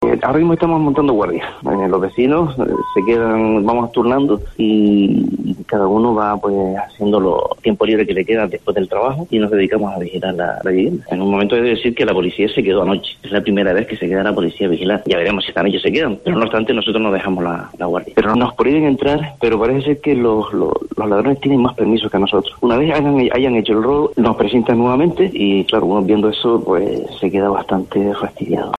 En nuestros micrófonos, nos contaba que “todos los vecinos nos encontramos muy afectados con esta última noticia.